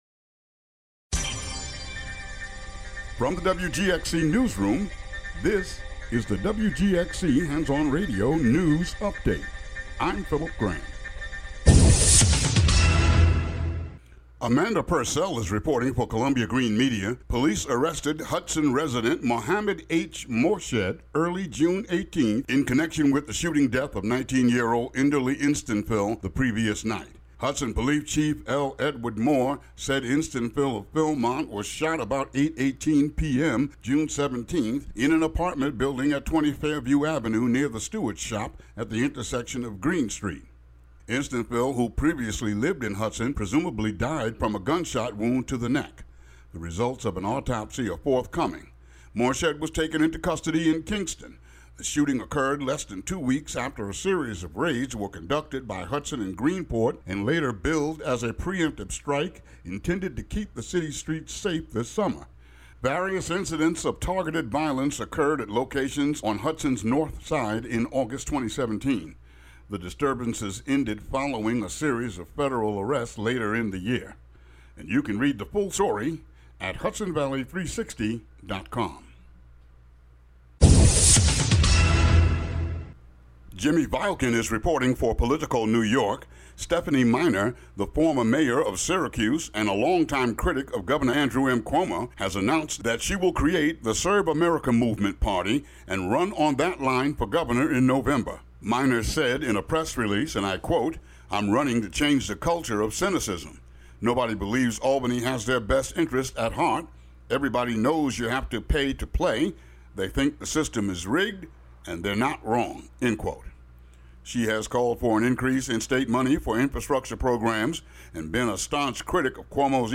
WGXC Local News Update Audio Link